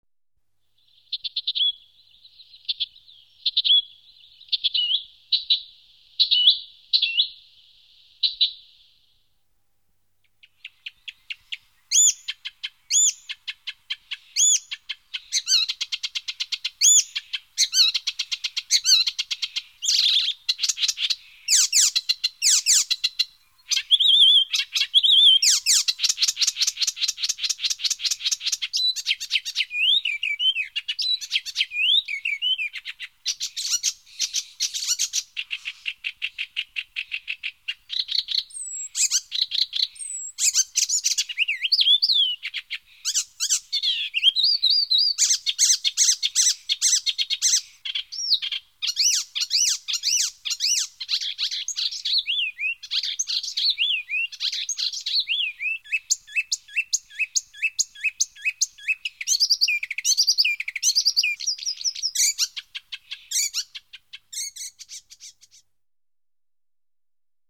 Голоса птахів
U Берестянка
hippolais.MP3